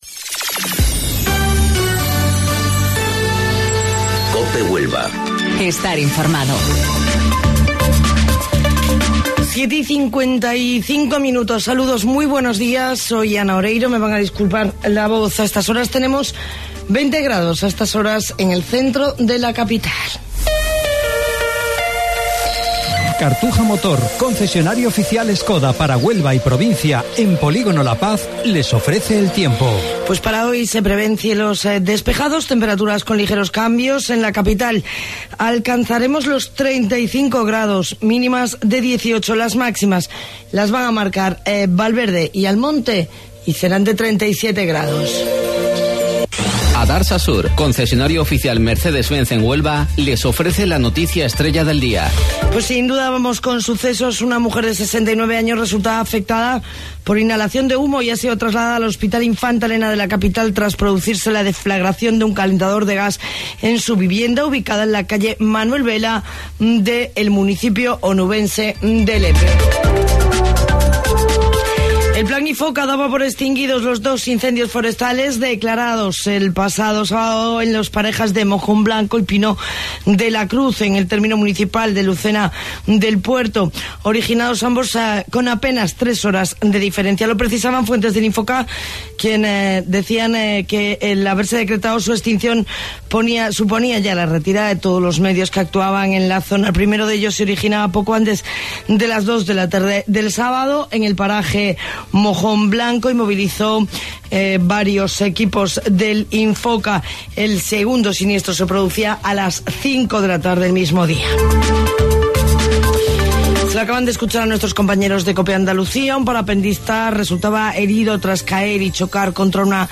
AUDIO: Informativo Local 07:55 del 5 de Agosto